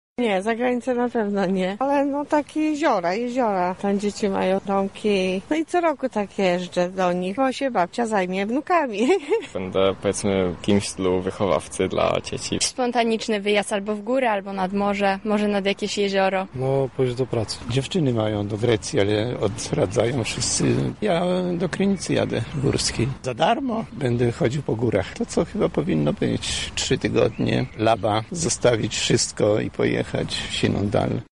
Zapytaliśmy spacerowiczów Ogrodu Saskiego, czy ta decyzja wpłynie na ich plany i jak zamierzają spędzić wczasy:
Sonda